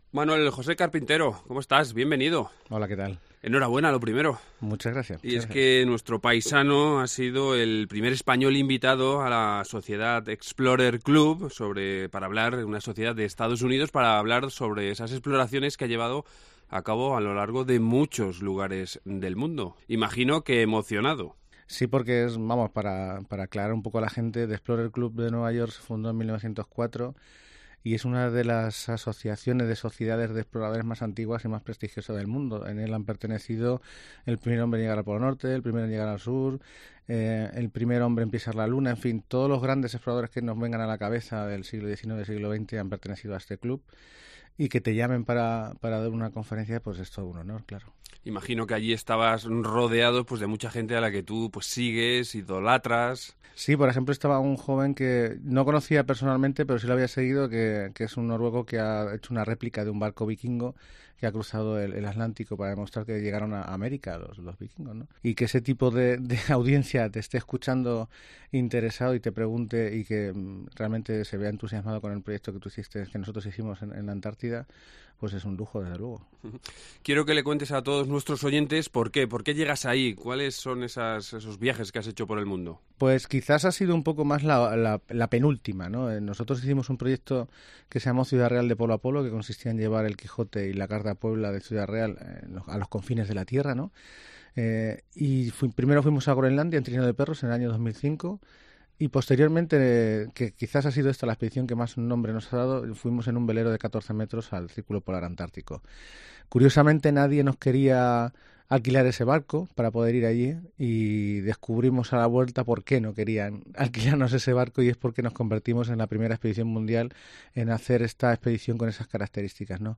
Y entre tanta expedición ha decidido hacer una parada en nuestros estudios.